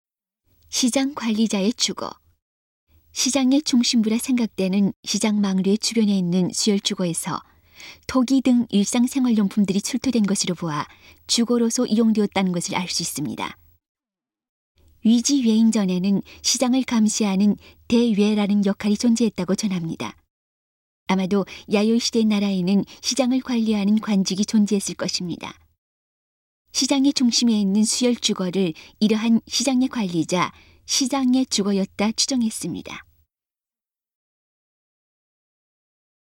시장의 중심부에 있는 시장의 관리자 「시장장」의 주거라 추정됩니다. 음성 가이드 이전 페이지 다음 페이지 휴대전화 가이드 처음으로 (C)YOSHINOGARI HISTORICAL PARK